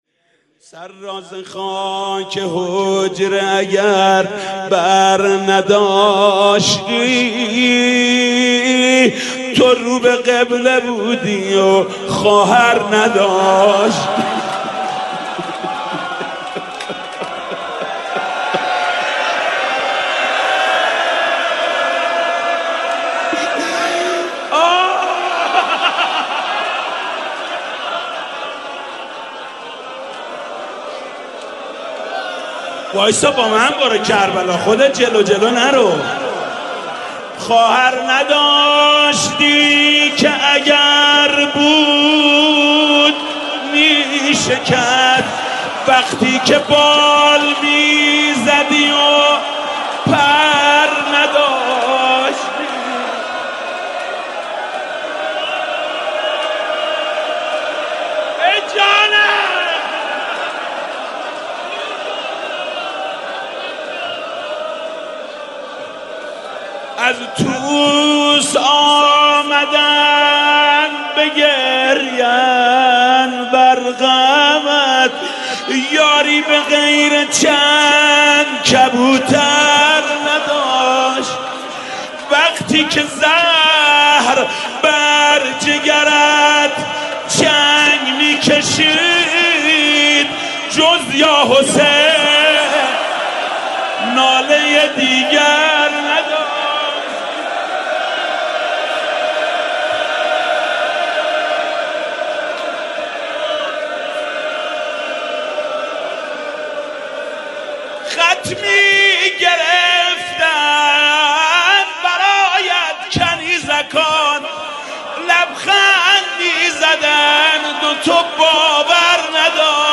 «شهادت امام جواد 1389» روضه: سر را ز خاک حجره اگر بر نداشتی